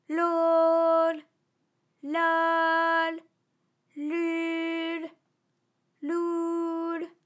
ALYS-DB-001-FRA - First, previously private, UTAU French vocal library of ALYS
lolaululoul.wav